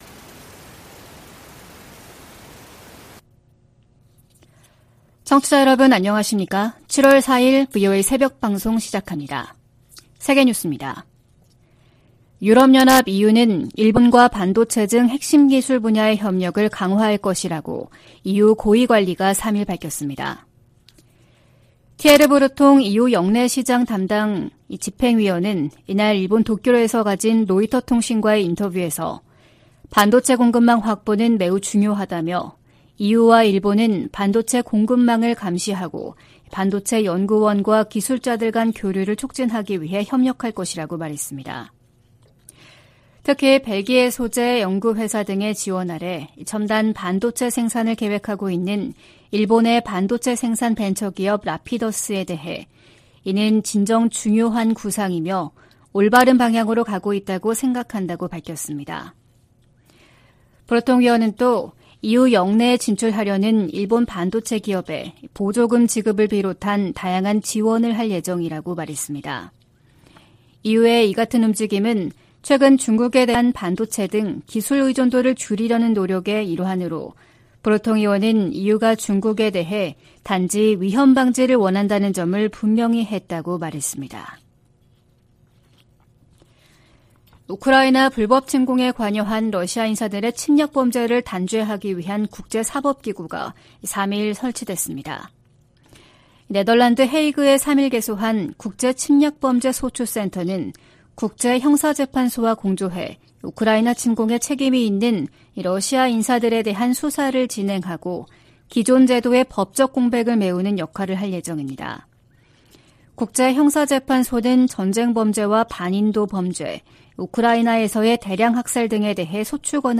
VOA 한국어 '출발 뉴스 쇼', 2023년 7월 4일 방송입니다. 최근 미국 의회에서는 본토와 역내 미사일 방어망을 강화하려는 움직임이 나타나고 있습니다.